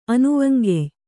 ♪ anuvaŋgey